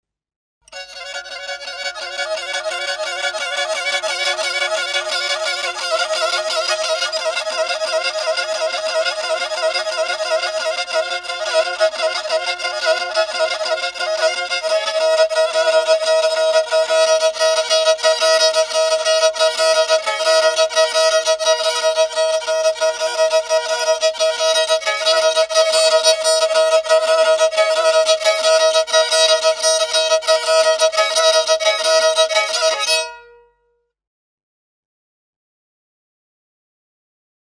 Trabzon parmak ucu sözsüz müziği
Sözsüz Yöresel Müzikler Dosya indirme linkiniz saniye içinde hazır olacaktır.